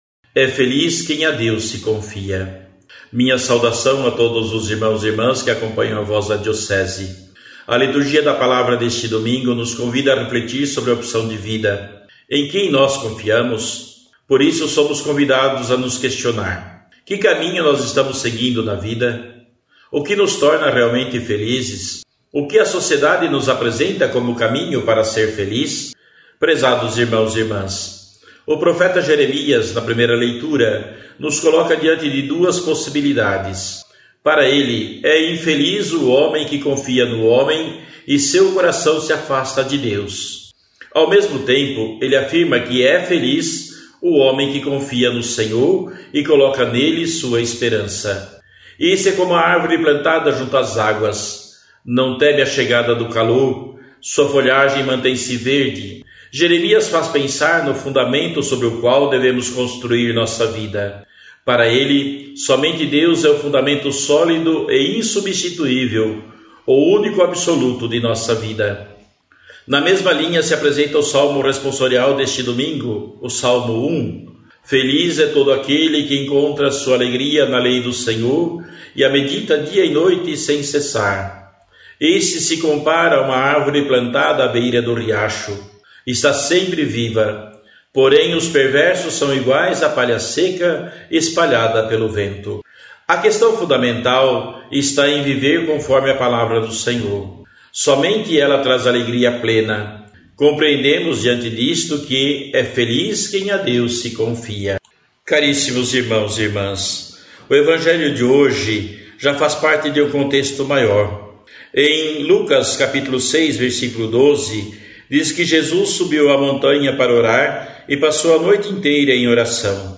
Dom Adimir Antonio Mazali – Bispo Diocesano de Erexim – RS